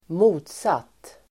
Uttal: [²m'o:tsat:]